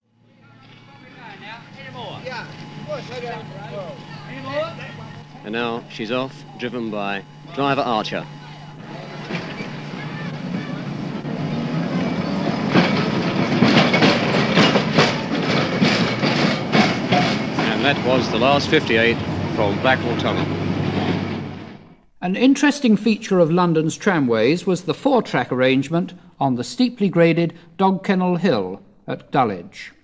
Londons Last Trams Stage 5 Live recordings